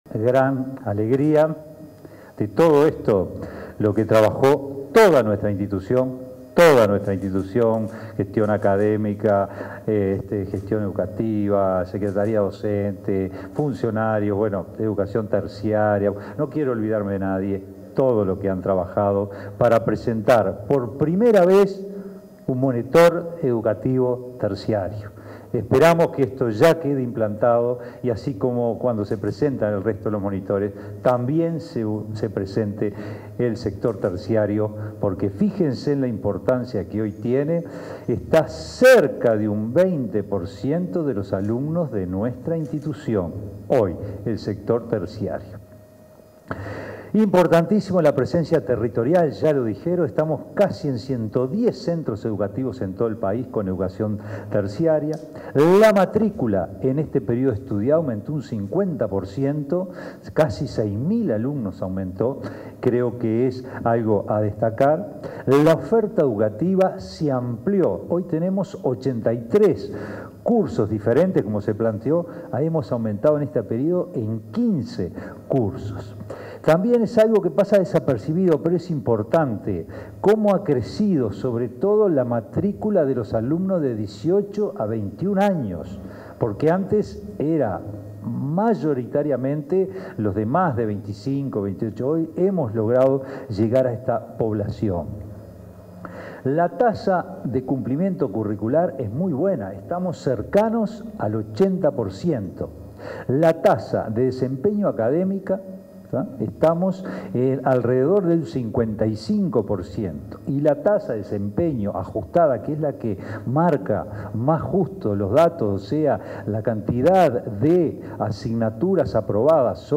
Palabras del director general de Educación Técnico Profesional, Juan Pereyra
Palabras del director general de Educación Técnico Profesional, Juan Pereyra 03/12/2024 Compartir Facebook X Copiar enlace WhatsApp LinkedIn En el marco de la ceremonia de presentación del Monitor de Educación Profesional, este 3 de diciembre, se expresó el director general de Educación Técnico Profesional (DGEIP-UTU), Juan Pereyra.